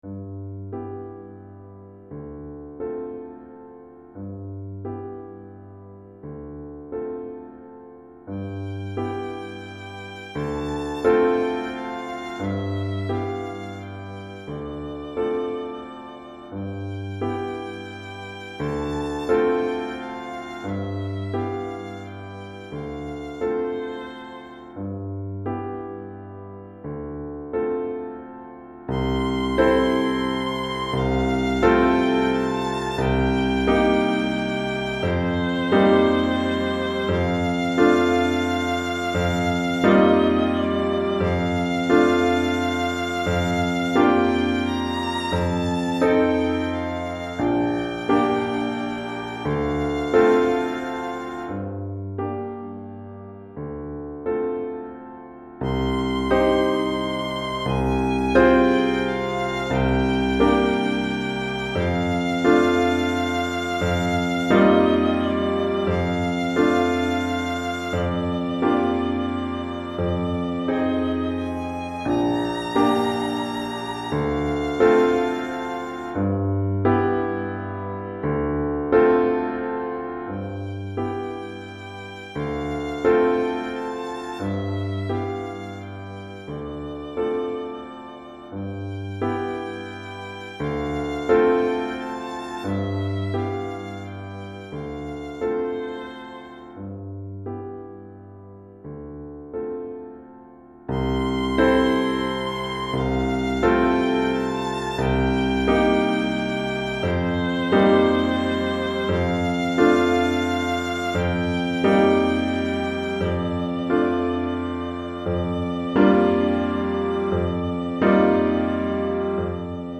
Violon et Piano